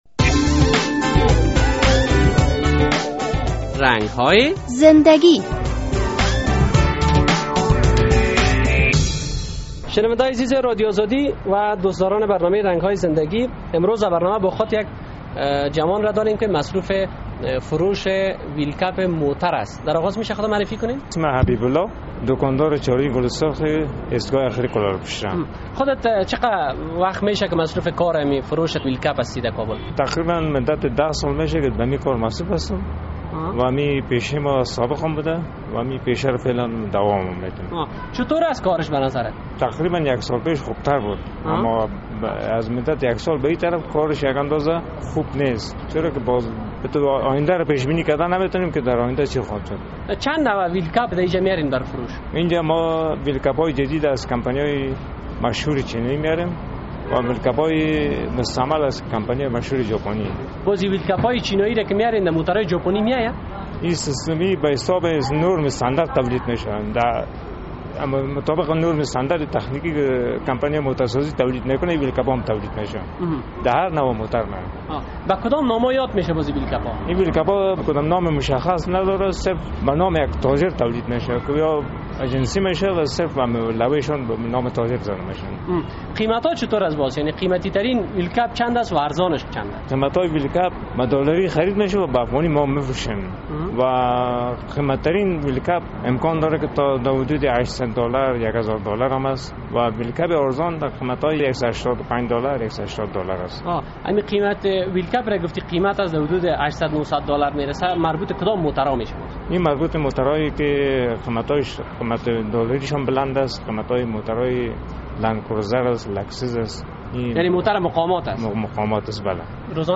افغان ها امروز از موتر های مودل نو بیشتر استفاده می کنند و بازار فروش پرزه جات آن در شهر کابل خصوصاً خوب است، در این برنامه با یک تن از تایر و ویل کپ فروش مصاحبه کرده ایم:...